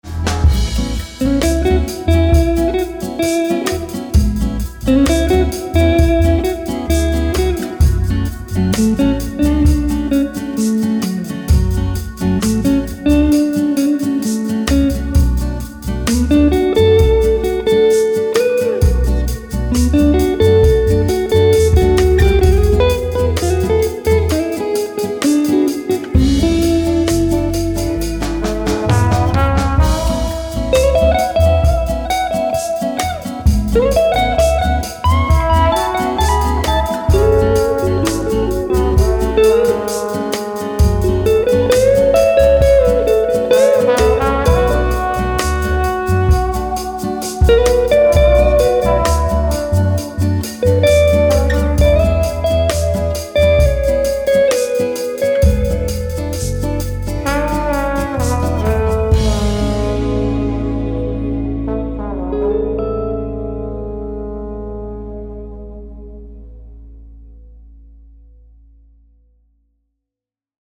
Then they pick up their instruments and improvise.
guitar, bass
trombone
piano
drums